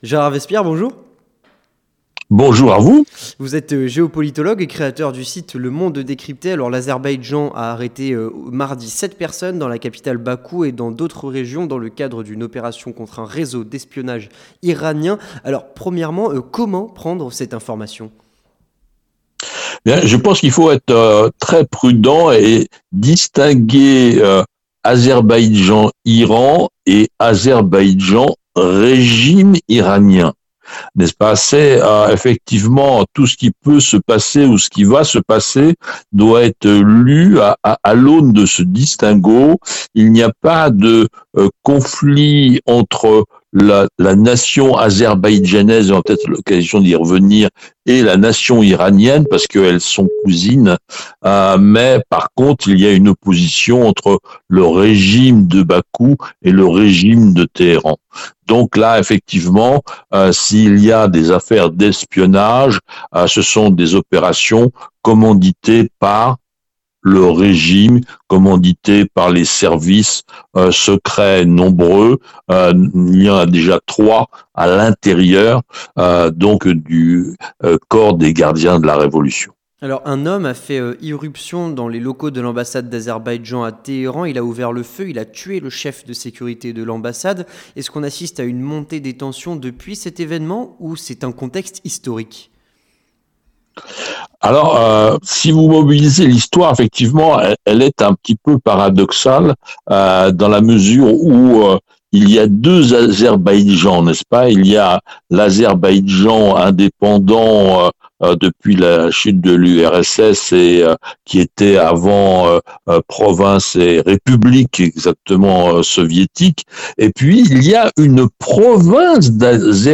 Entretien du 18h (02/02/2023)